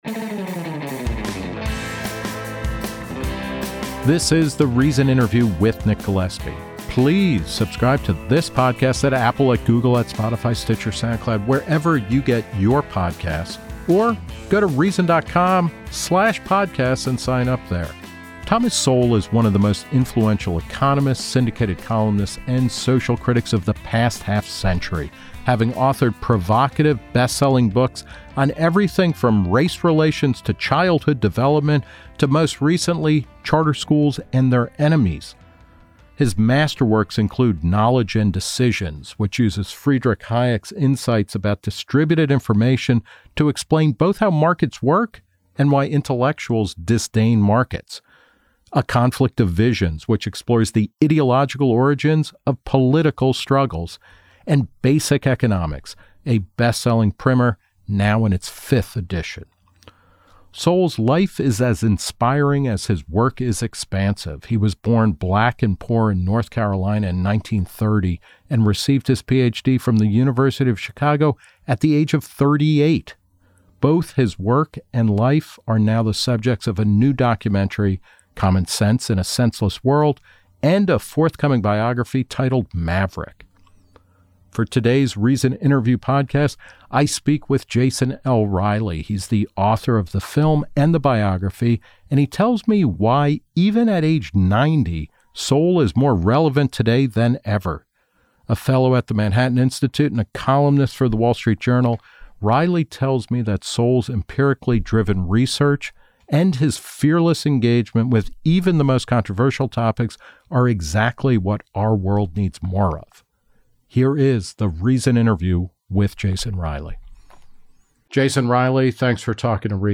Nick Gillespie speaks with Jason L. Riley, the author of a new documentary and forthcoming biography of 90-year-old economist Thomas Sowell, who has written widely and deeply about race, child development, education, and politics.